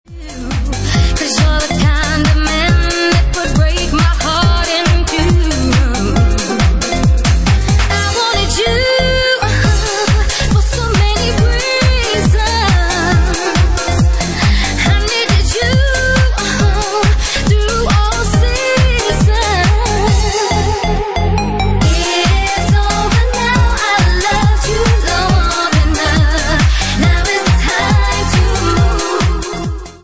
Bassline House at 139 bpm